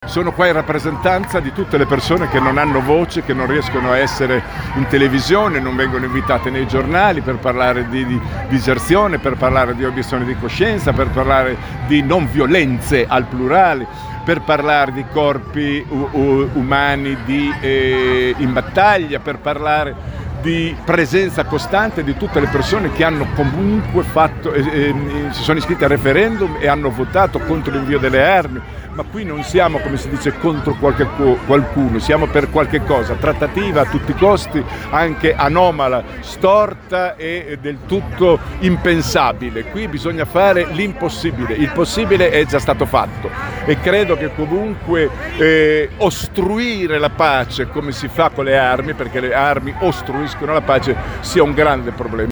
Tra loro lo scrittore Alessandro Bergonzoni, che abbiamo raggiunto: